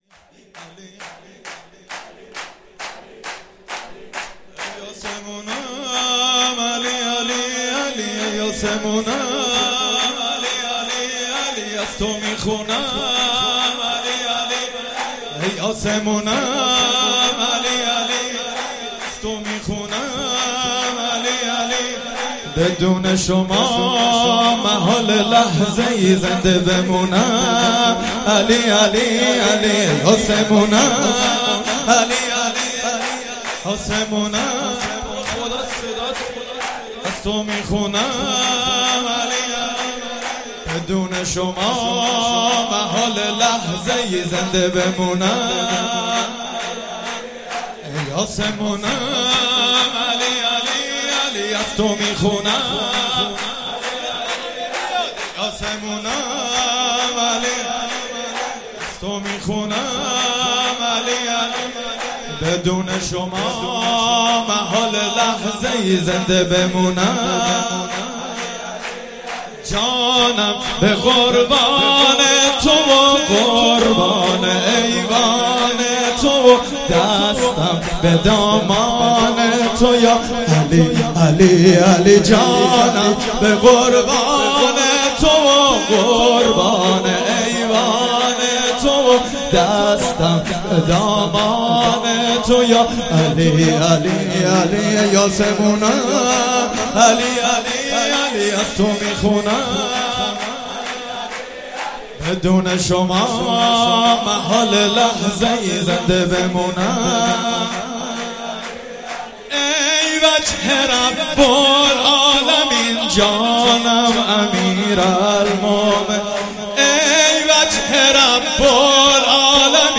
مراسم عید غدیر 16 شهریور 96
چهاراه شهید شیرودی حسینیه حضرت زینب (سلام الله علیها)
شعرخوانی